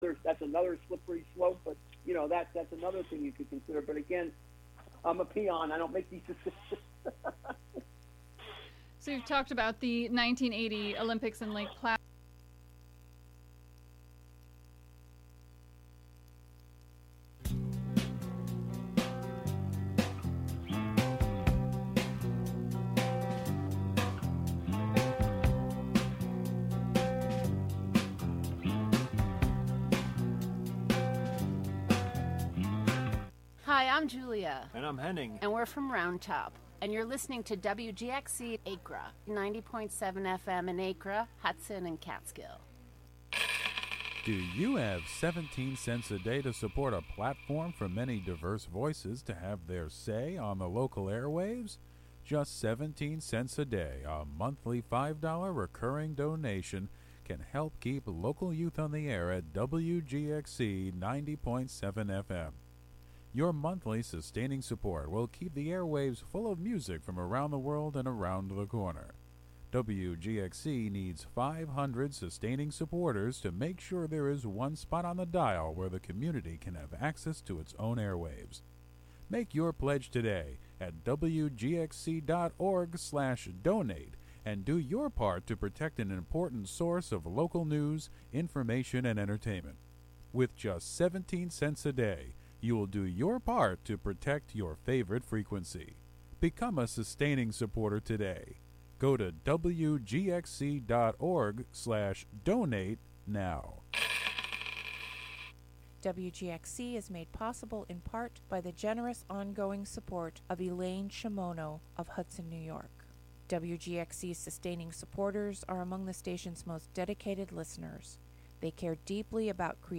"Movies on the Radio" is a showcase for sounds synchronized to experimental film and video work by artists from Columbia and Greene counties and across the world.
Listen for soundtracks of old jazz, analog noise, psychogeographic musings, and more along with interviews with media artists and makers from Philmont, and beyond.